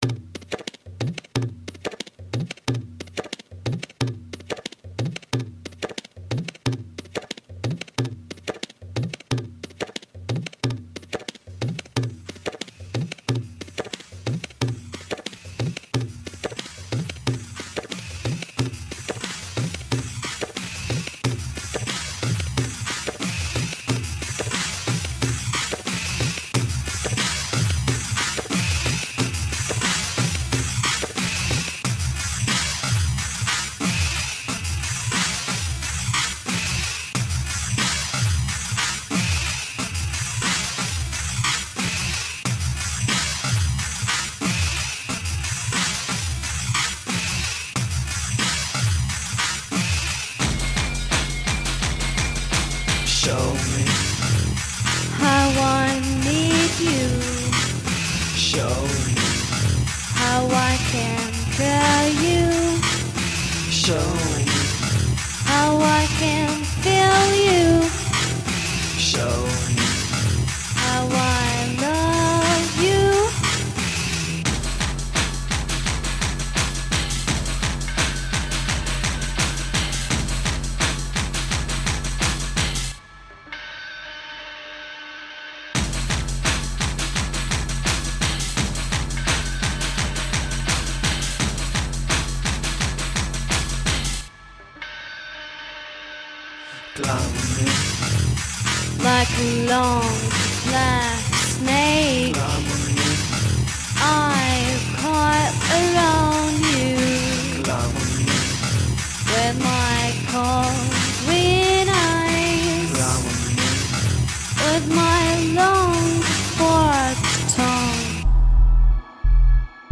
Groovy, french downtempo electro-rock songs.